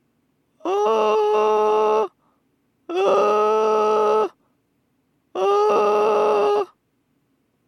吸気の裏声が上手く鳴らせないパターン1
ギリギリ・ガリガリした音が混じってしまう
音量注意！
概要で解説した通り、基本的に吸気で発声しようとすると声帯は開こうとします。それに対抗する形で閉鎖しないと声にならないので、声帯を上手く丁度いいテンションで閉じてあげる必要があるのですが、ここが非常に難しく慣れない内は声帯のテンションを強めすぎてしまったり、声道／共鳴腔をぎゅっと狭くしてしまい、サンプル音声のように欲しくないノイズっぽい音色が鳴ってしまいます。